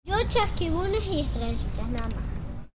小学生放学
描述：固定在一所小学的入口处录音。 首先是平静的，然后有越来越多的小孩的父母来接孩子。有很多聊天声。孩子们热情洋溢。孩子们跑来跑去，大喊大叫。记录：Rode NT4gt; Sony PCM D50。
标签： 人声 闲聊 环境 孩子 环境音 声景 谈论 实地录音 背景音 学校 一般噪音 公园 语音 叫喊 氛围 奔跑
声道立体声